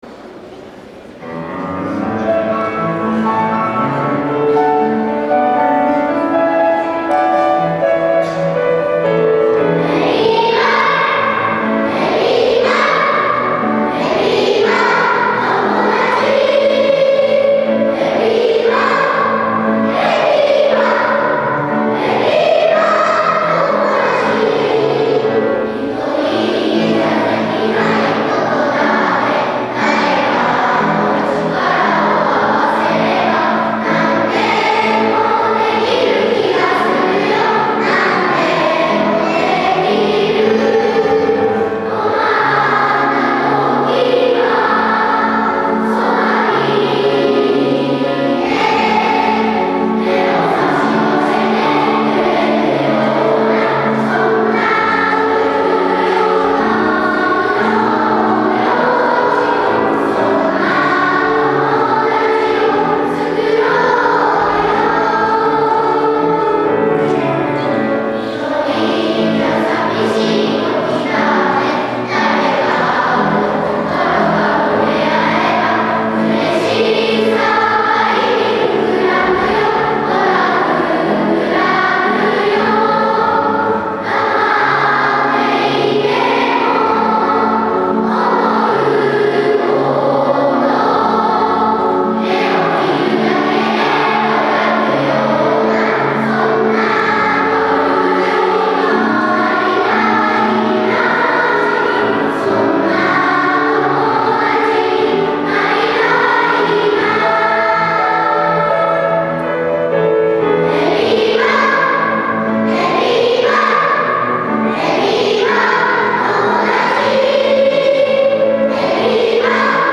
講堂まで案内すると、全校２部合唱「EVVIVA　友だち」を歌う子どもたちのハーモニーが講堂中に響いています。
そのハーモニーでゲストのみなさんを歓迎します。